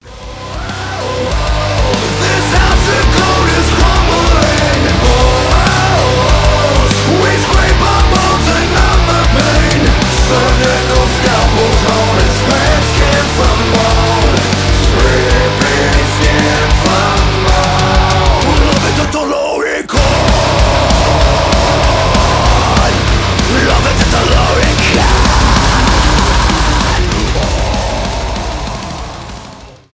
thrash metal